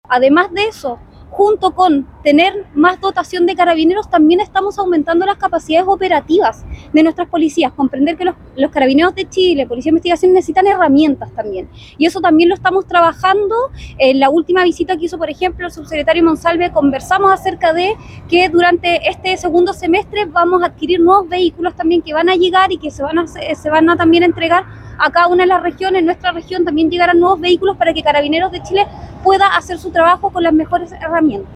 Moreira anunció que se continuará con los aumentos de dotación, pero hay plazos y procesos y normativa que cumplir. Al mismo tiempo, recordó que se está aumentando las capacidades operativas de las policías y anunció que en este segundo semestre serán adquiridos nuevos vehículos.